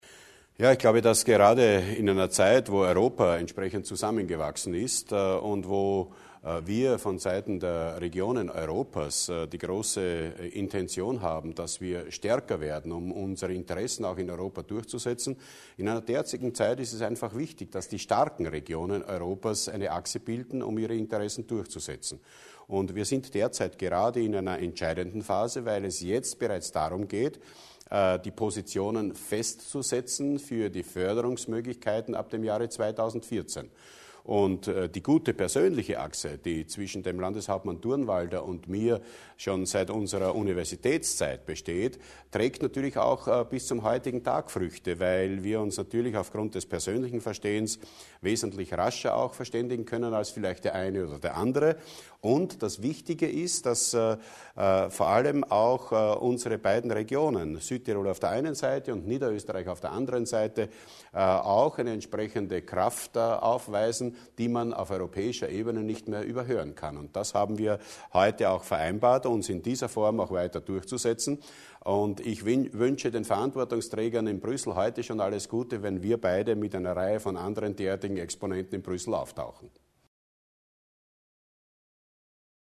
Landeshauptmann Pröll über das Treffen mit Landeshauptmann Durnwalder